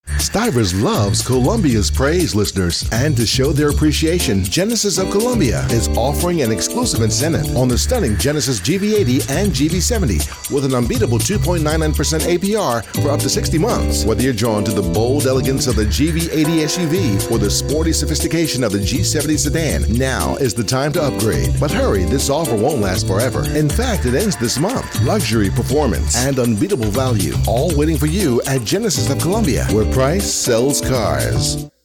Jamaican, Southern, Corporate, Natural and mature
Middle Aged